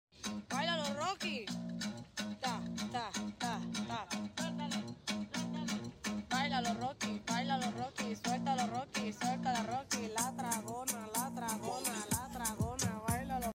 This Adorable Cat Dance Dembow sound effects free download